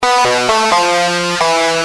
Lead_b5.wav